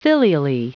Prononciation du mot filially en anglais (fichier audio)
Prononciation du mot : filially